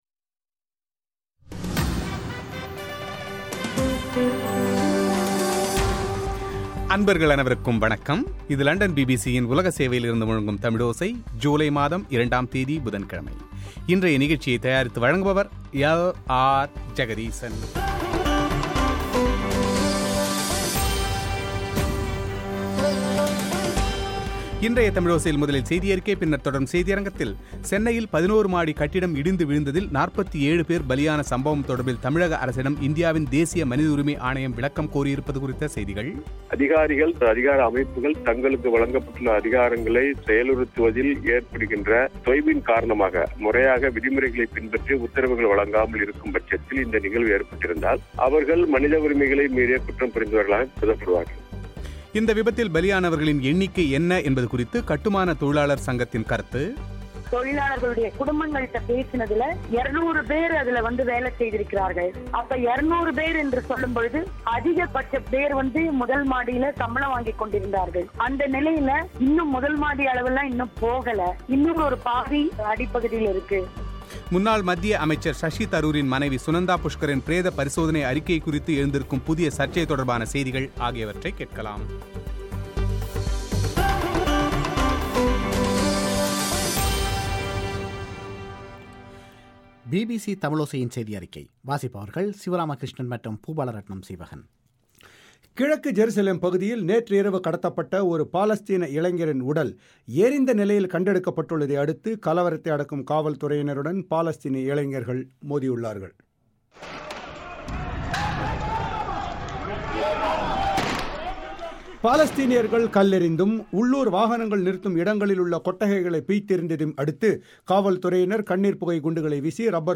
சென்னையில் 11 மாடி கட்டிடம் இடிந்து விழுந்ததில் 47 பேர் பலியான சம்பவம் தொடர்பில் தமிழக அரசிடம் இந்தியாவின் தேசிய மனித உரிமை ஆணையம் விளக்கம் கோரியிருப்பது குறித்து தமிழ்மாநில மனித உரிமை ஆணைய உறுப்பினர் கே.பாஸ்கரன் பிபிசி தமிழோசைக்கு அளித்திருக்கும் பிரத்யேக செவ்வி;